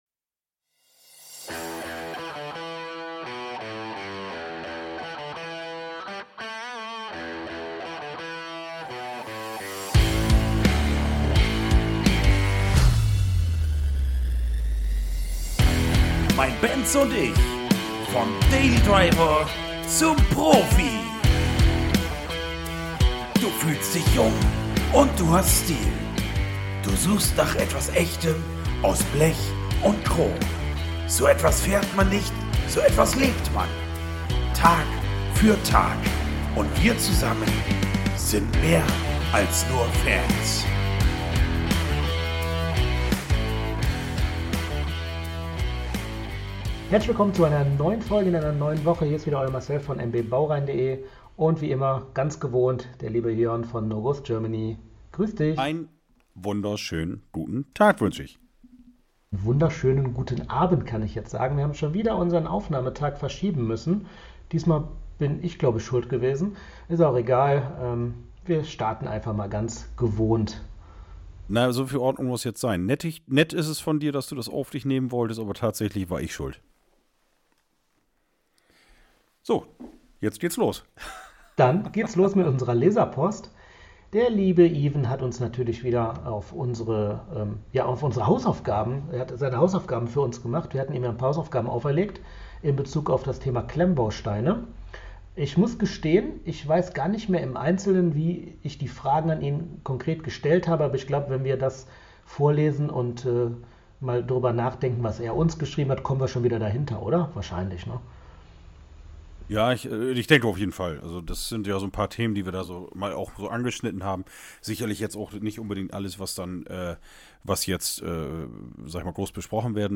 defekte Tonspur